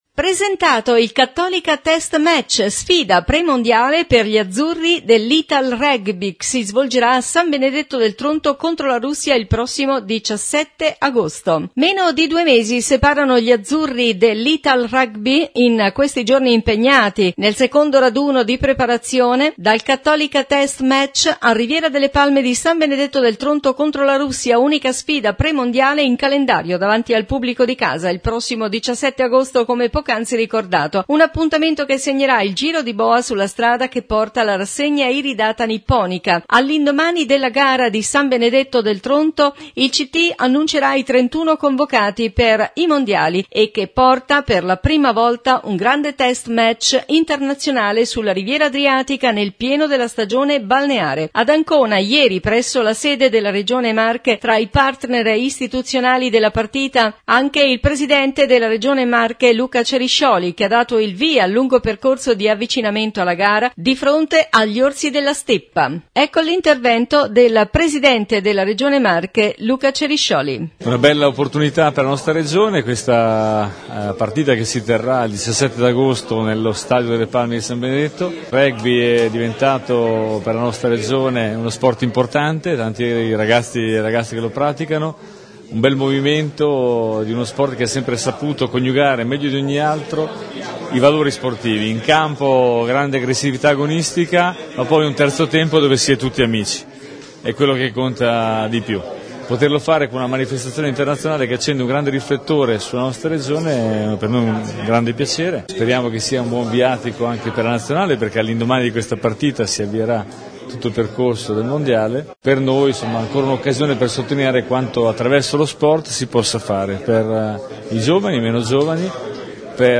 New Radio Star | Notizie Regione … Presentato il Cattolica Test Match, sfida pre-mondiale per gli Azzurri dell’Italrugby che si svolgerà a San Benedetto del Tronto contro la Russia, il prossimo 17 Agosto. Interviste : Luca Ceriscioli – Presidente Regione Marche